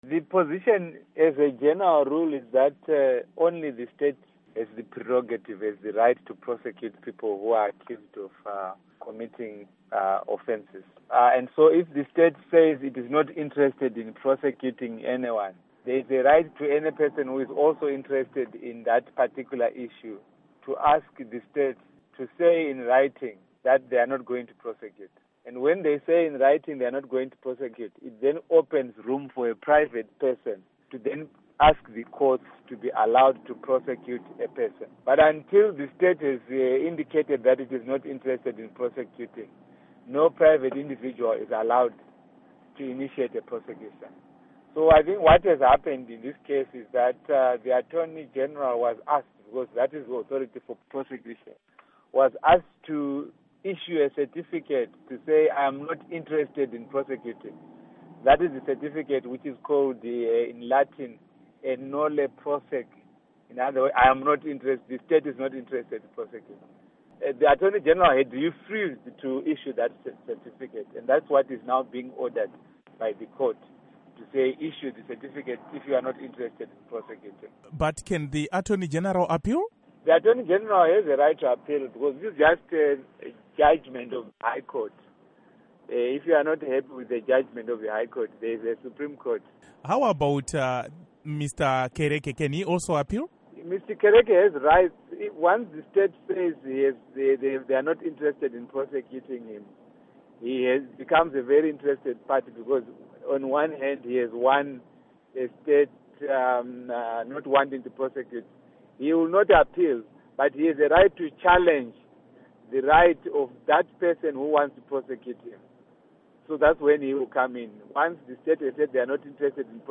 Interview With Munyaradzi Kereke